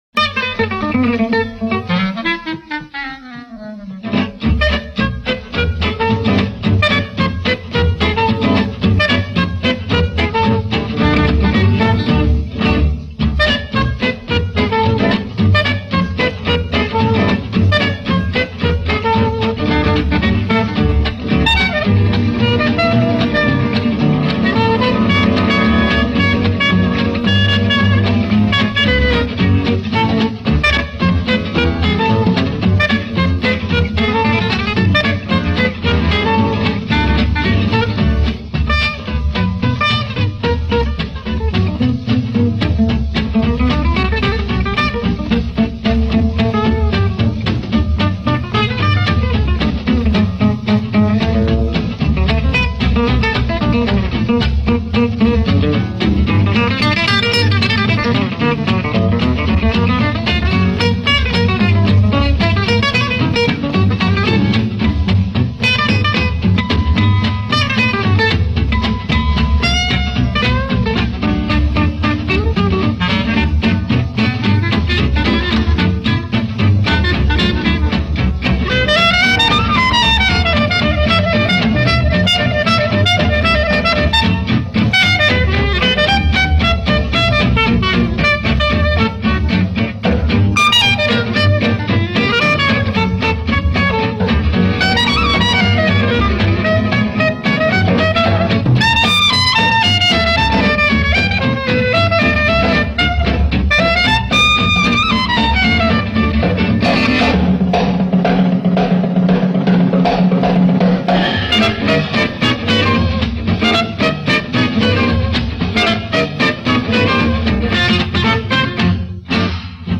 بی کلام